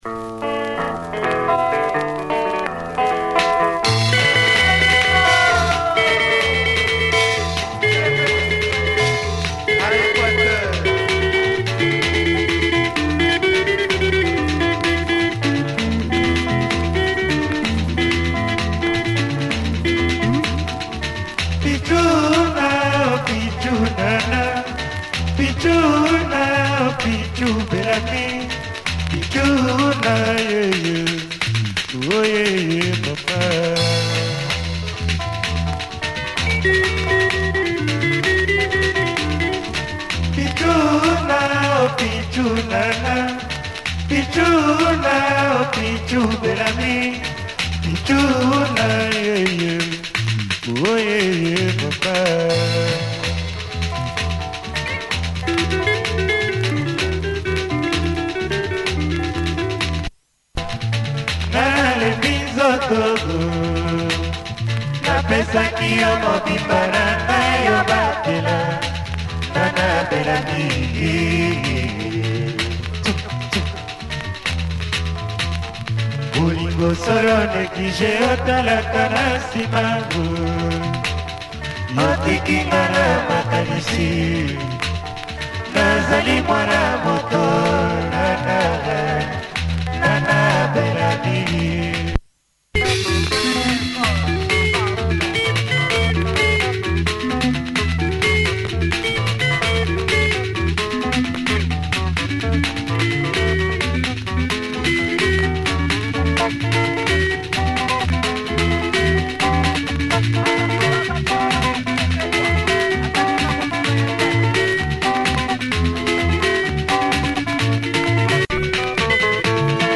Great Congo track